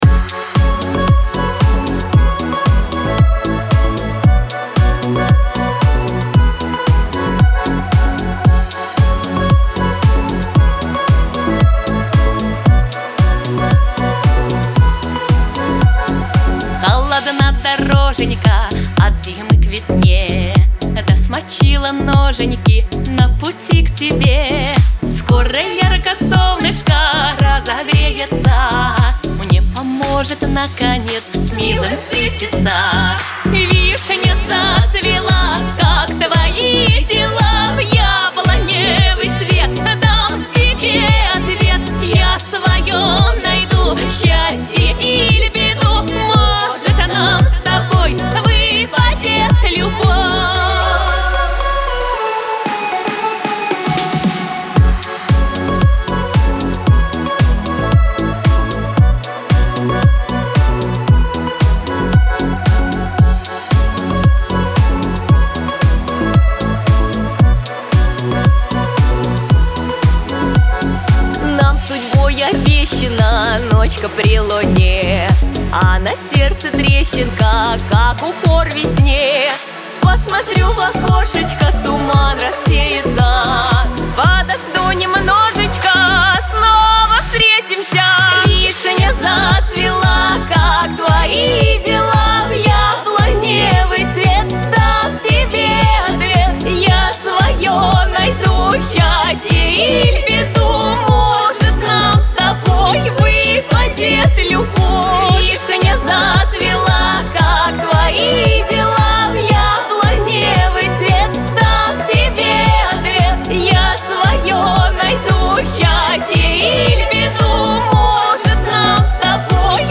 дуэт
Лирика